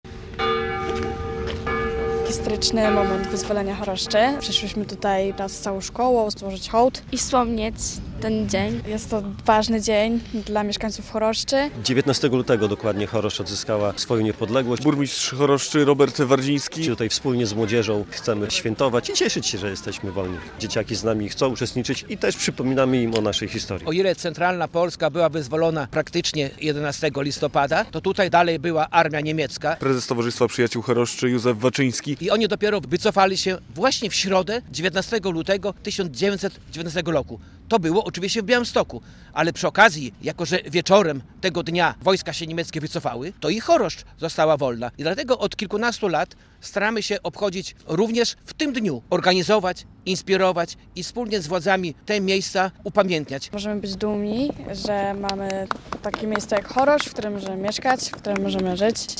106. rocznica wyzwolenia Choroszczy - relacja